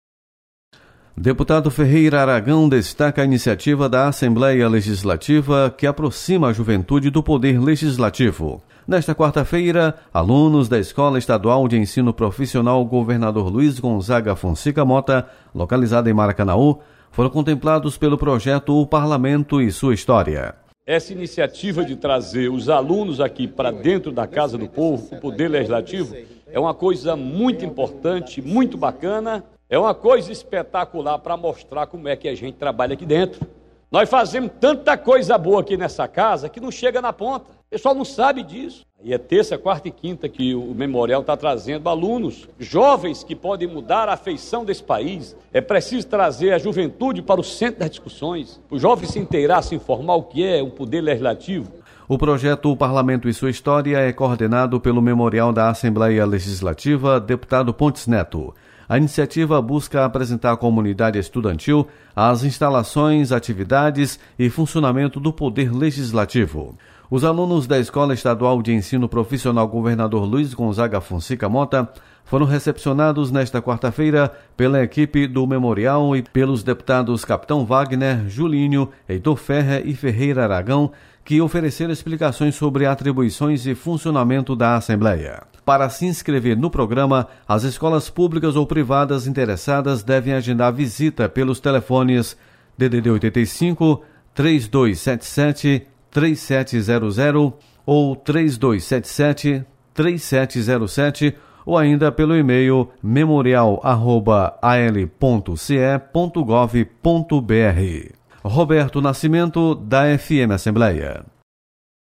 Deputado Ferreira Aragão destaca projeto o Parlamento e sua História. Repórter